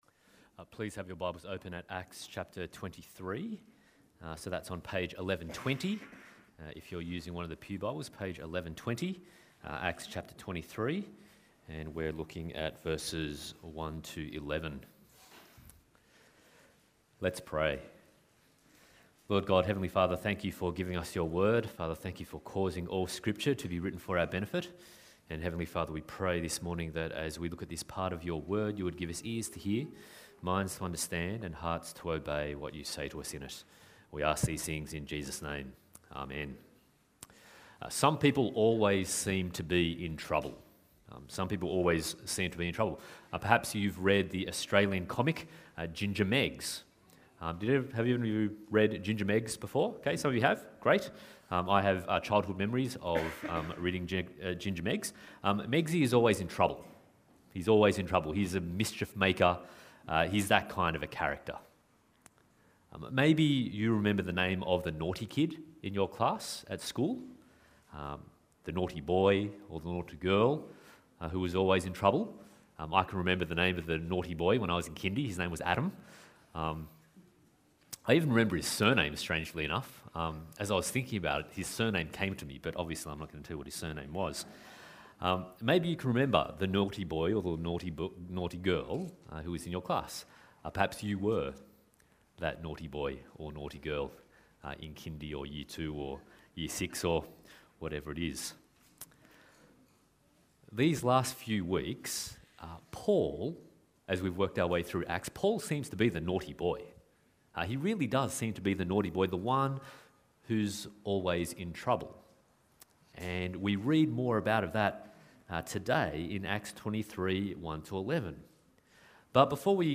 Acts 23:1-11 Service Type: Sunday Morning « Paul’s Citizenship